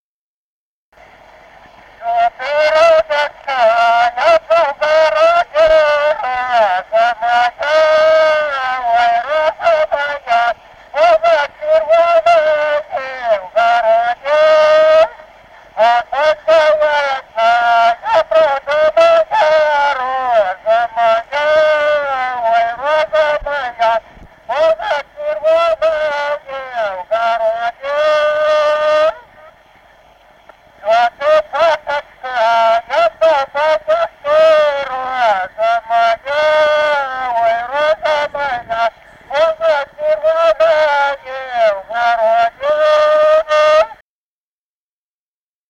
Народные песни Стародубского района «Что ты, розочка», новогодняя щедровная.
д. Камень.